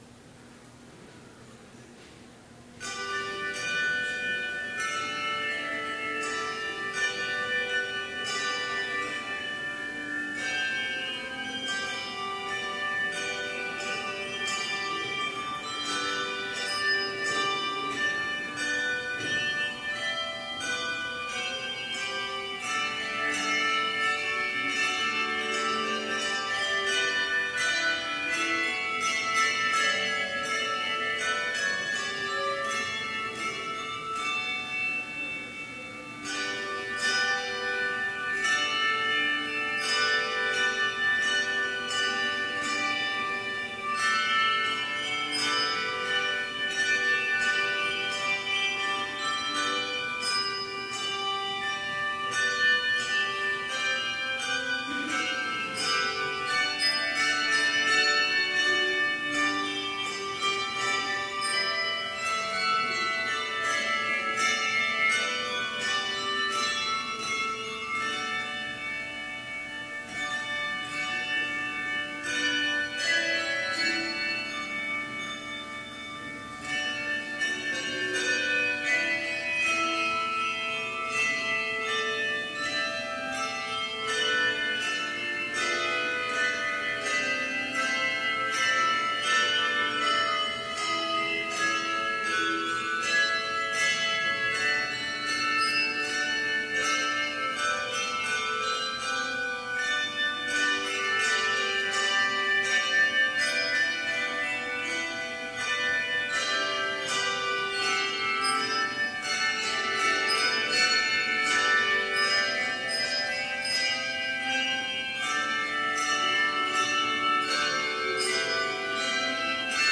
Complete service audio for Chapel - May 3, 2017